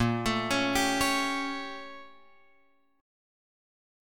A#mM13 chord {6 x 7 6 8 9} chord